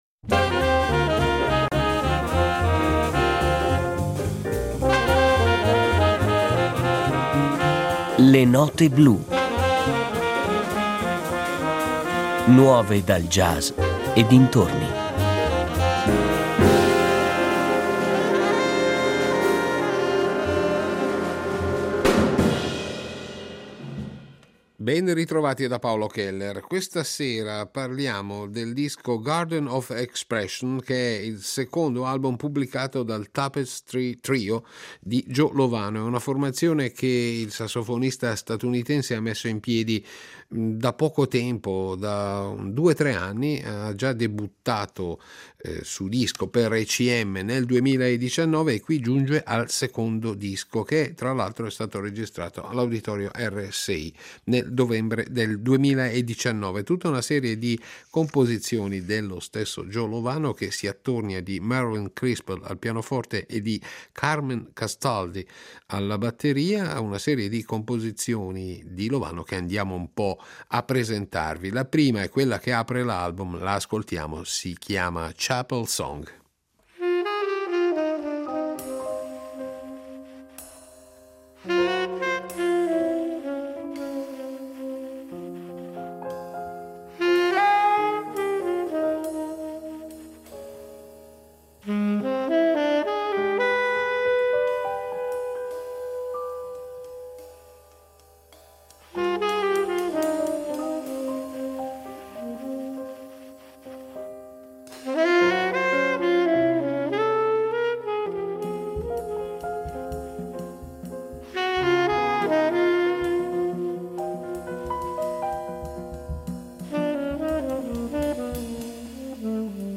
jazz
sax tenore, tarogato e percussioni